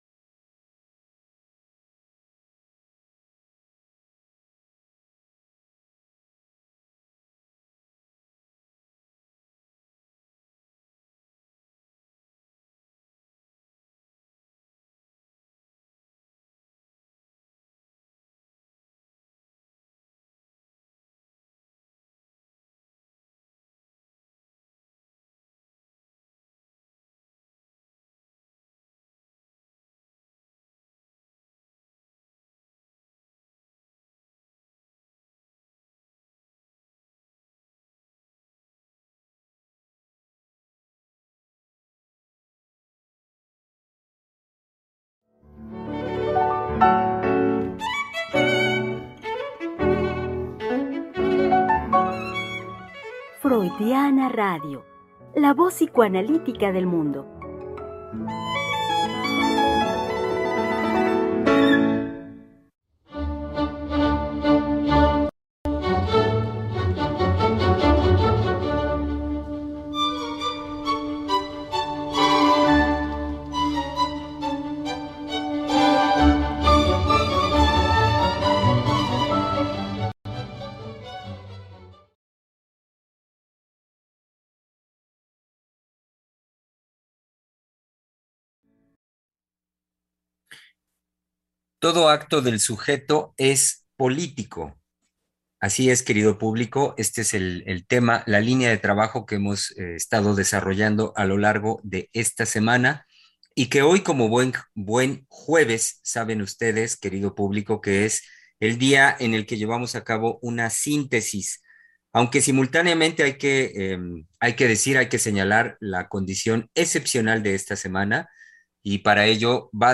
Programa transmitido el 11 de noviembre del 2021.